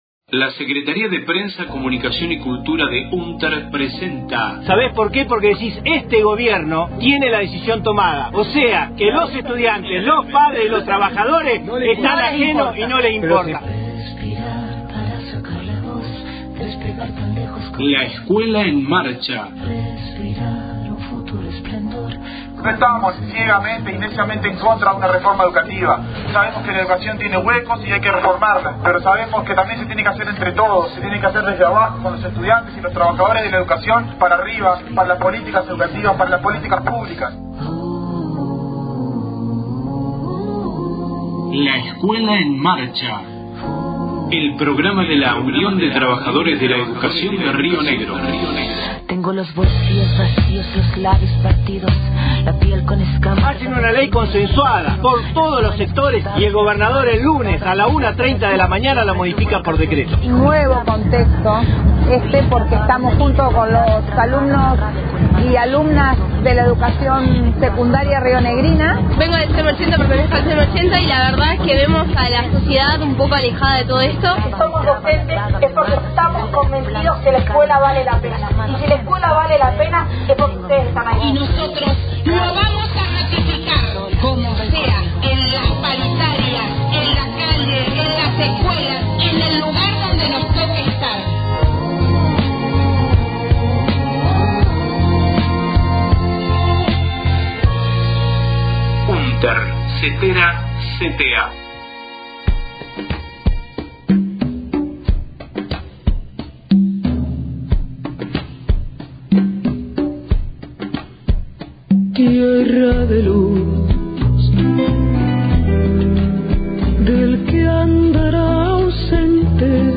Audios movilización y acto en Neuquén Capital el 4 de abril, 12 años del asesinato del Compañero Carlos Fuentealba se exige Justicia Completa